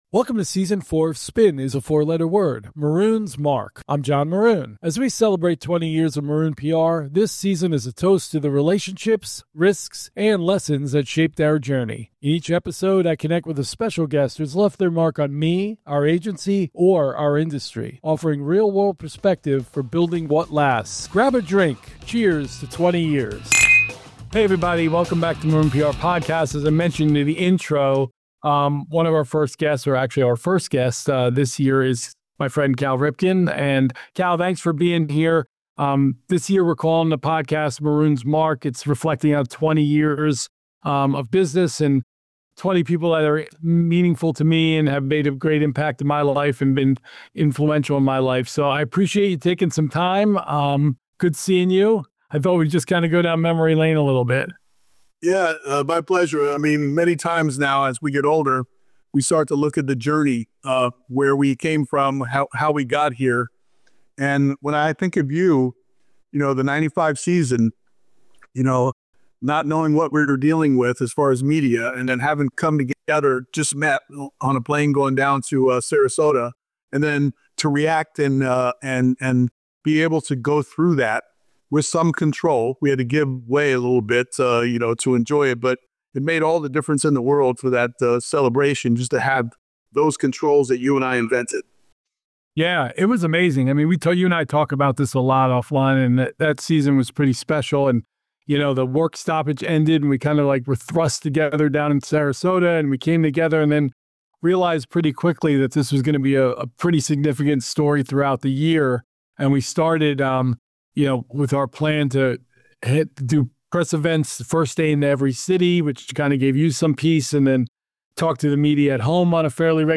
From the 1995 consecutive games’ streak to today’s Foundation work, their conversation reveals how lasting careers—and brands—are built: with consistency, composure, and showing up when it matters most.